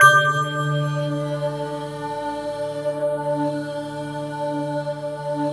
SYN BREATH1.wav